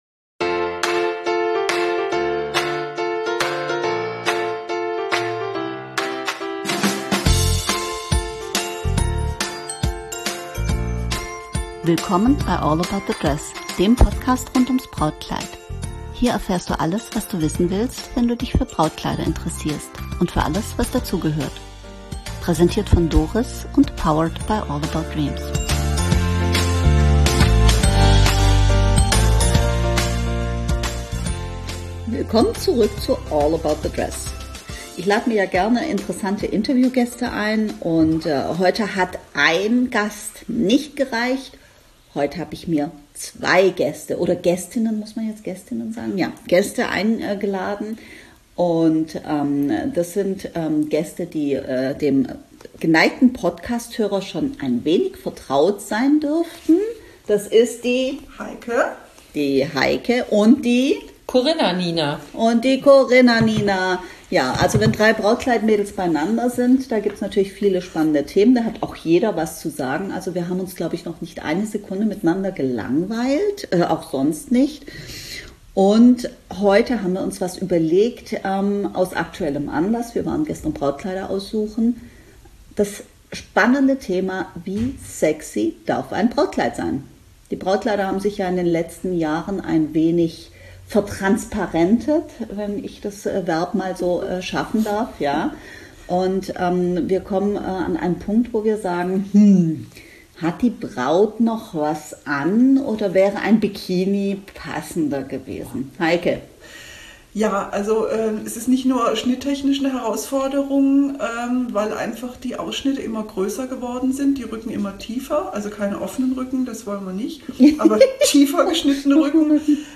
Wenn drei Brautkleid Mädels plaudern, kann das nur heiter sein.